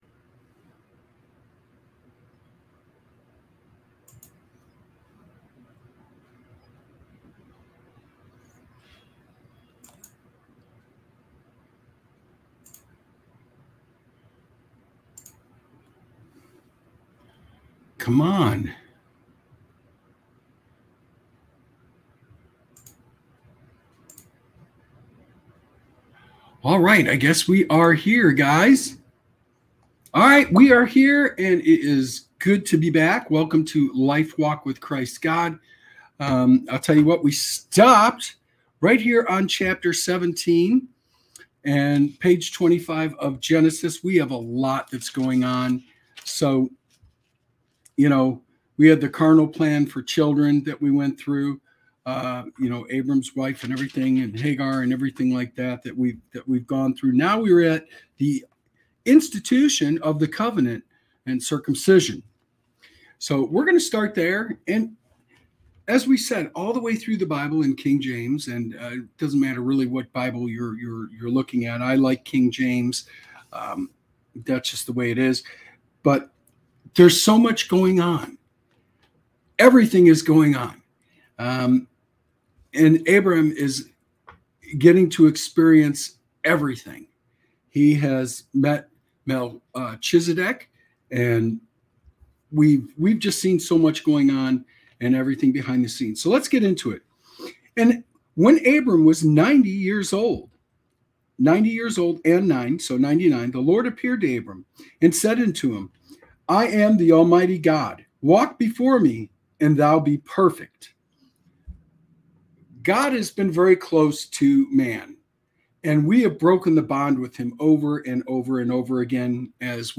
This show offers a reading into the teachings of Jesus Christ, providing insights into the Bible. Through engaging readings, heartfelt testimonies, and inspiring messages, Lifewalk with Christ God aims to: Strengthen faith: Explore the power of prayer, worship, and devotion.